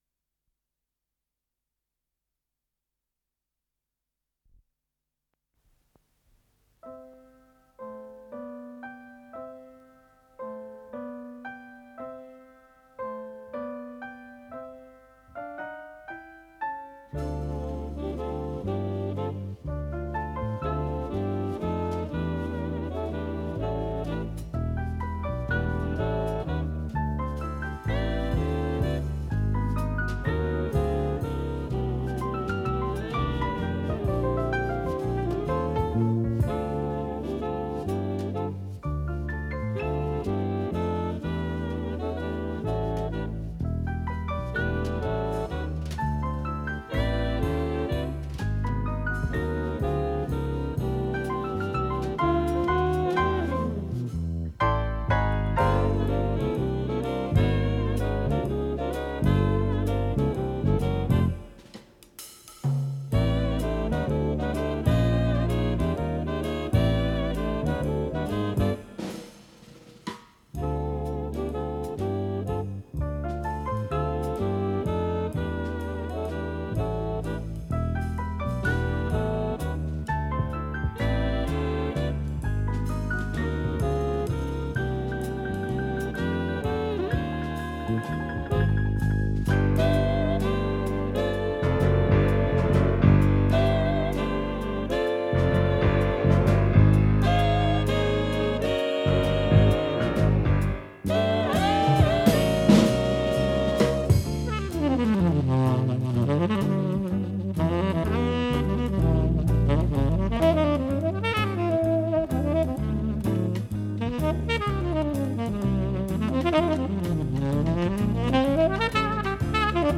ВариантДубль стерео